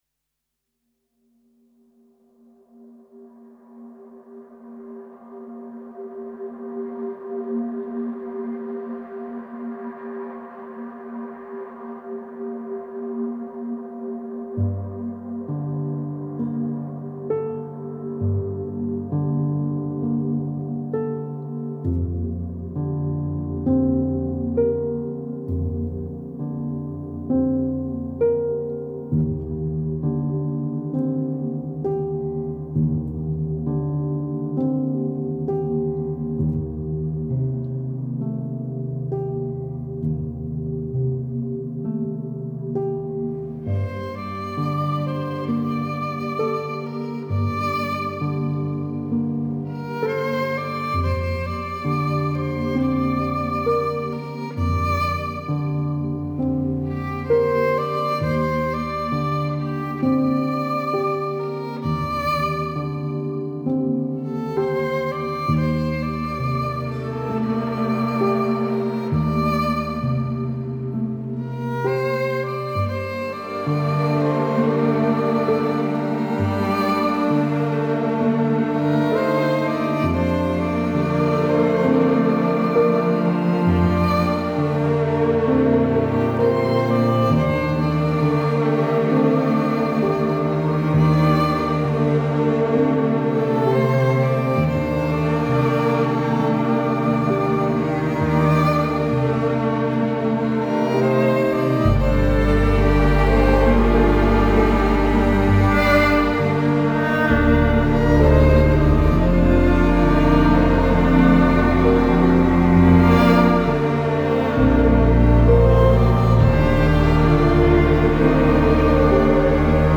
new age instrumental music